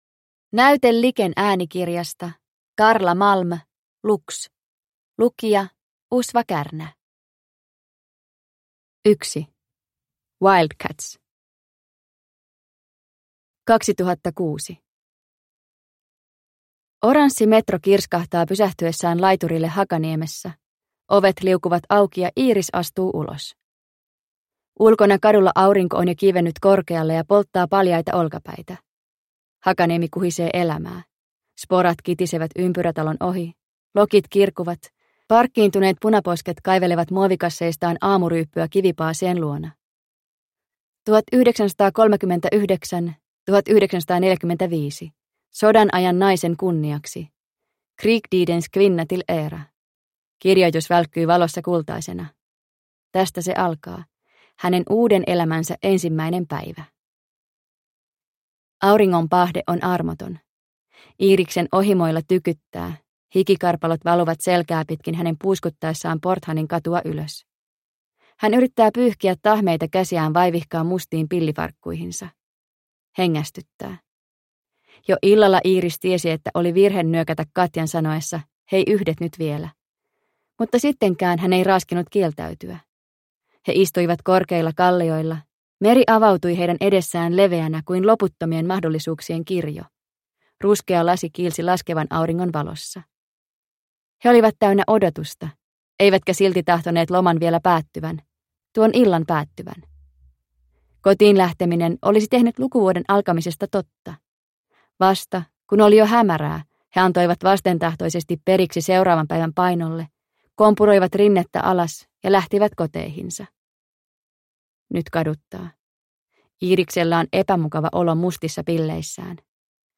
Lux – Ljudbok – Laddas ner